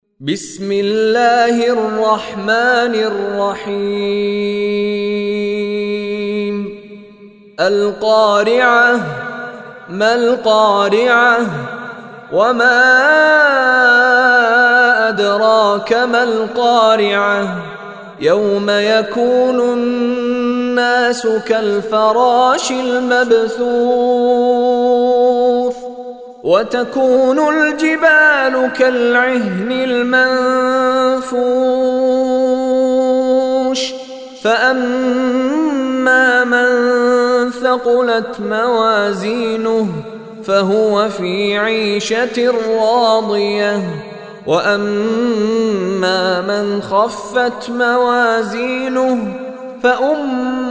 Surah Qariah Recitation by Mishary Rashid Alafasy
Surah Qariah is 101 chapter of Holy Quran. Listen online and download beautiful Quran tilawat / recitation of Surah Qariah in the voice of Sheikh Mishary Rashid Alafasy.